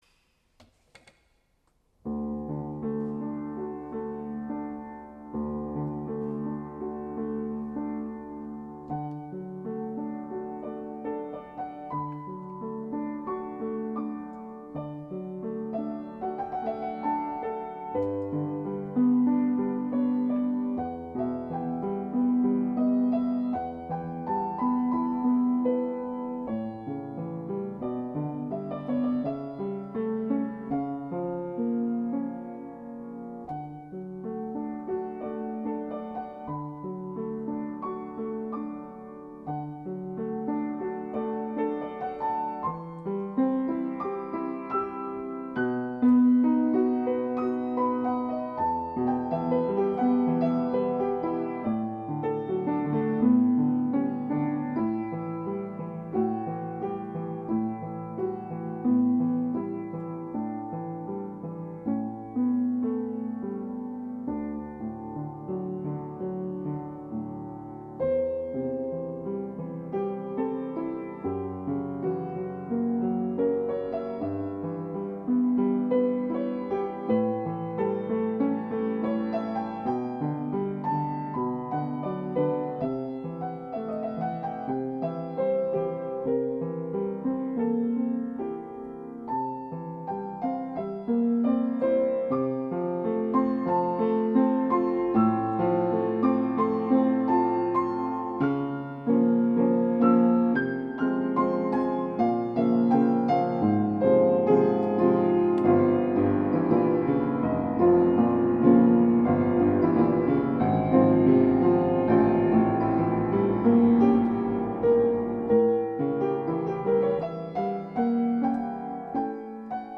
ピアノコンサート２
自宅のGPによる演奏録音 　　 デジピによる演奏録音